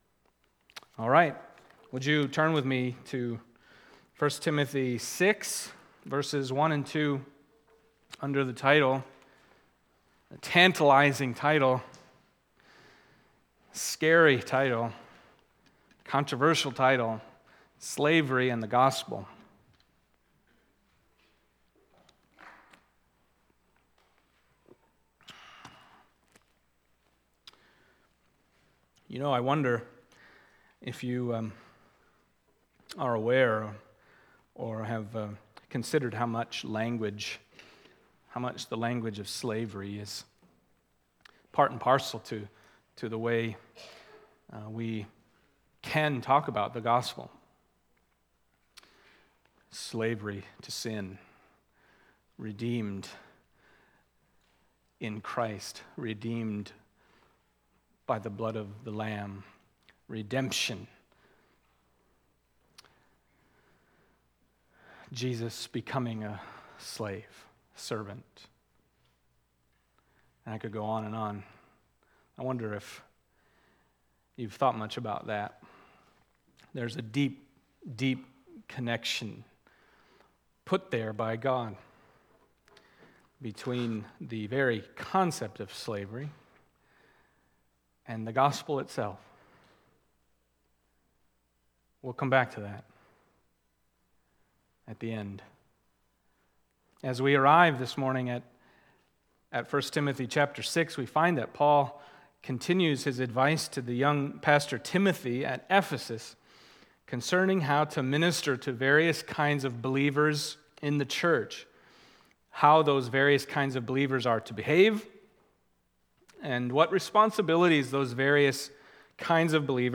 Passage: 1 Timothy 6:1-2 Service Type: Sunday Morning